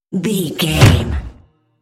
Trailer dramatic hit
Sound Effects
Atonal
heavy
intense
dark
aggressive